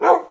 sounds / mob / wolf / bark2.mp3
bark2.mp3